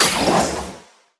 safe_open_char_01.wav